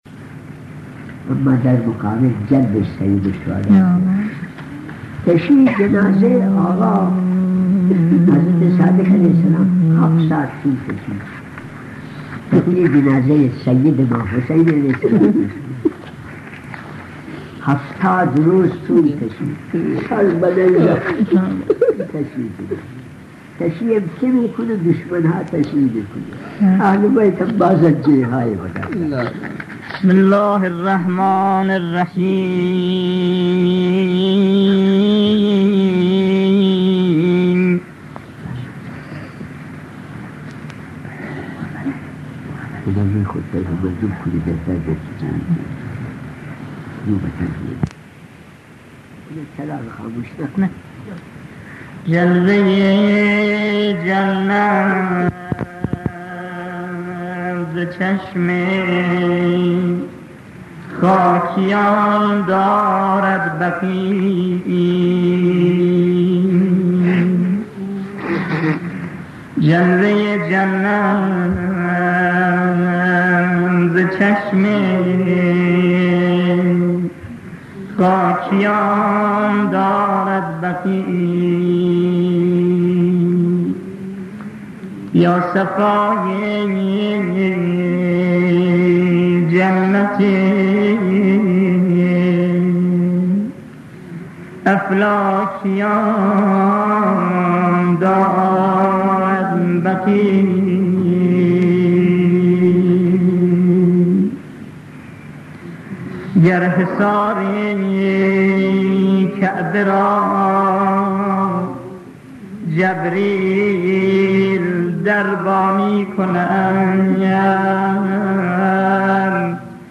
۱۸ – سخنرانی های جلسه چهارم اخلاقی